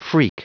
Prononciation du mot freak en anglais (fichier audio)
Prononciation du mot : freak